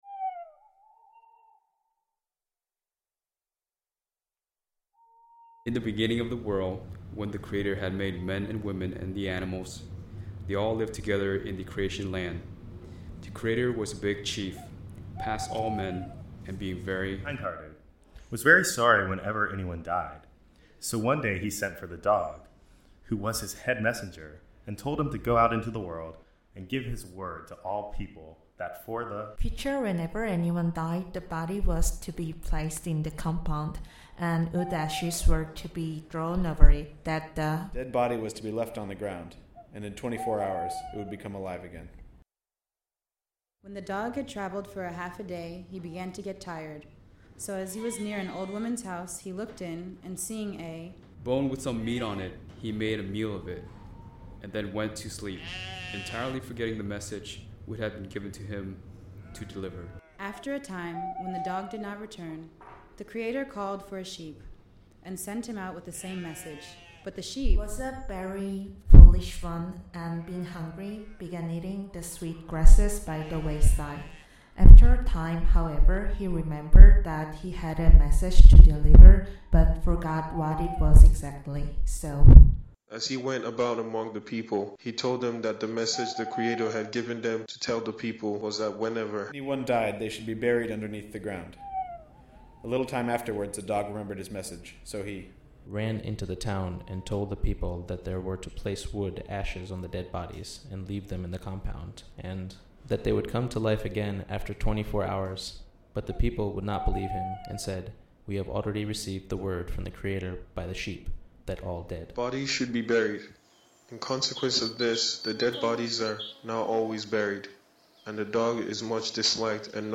• Why People Don’t Trust The Dog, an audio documentary
As most folktales do, it evokes a communal sense of shared experience, and the way a culture attempts to make sense of the unknown. We felt that that this can be best captured through recording each phrase by a different individual from another culture.